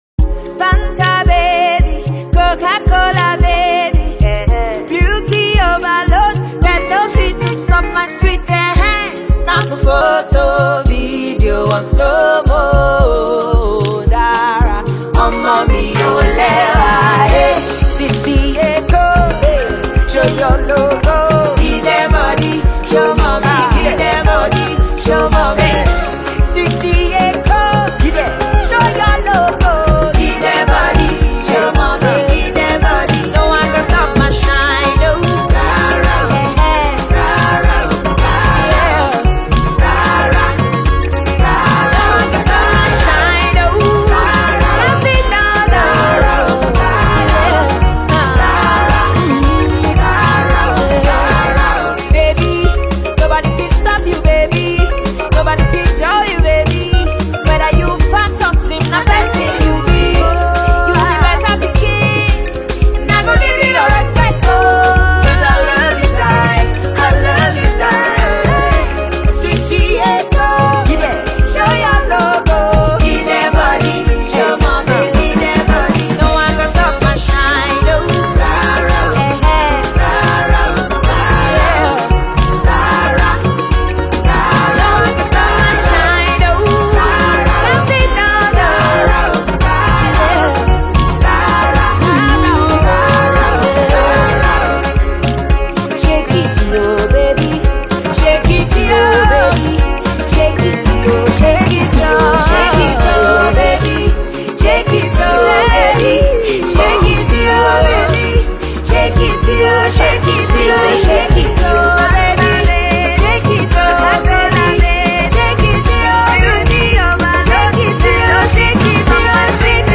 a Nigerian female singer and composer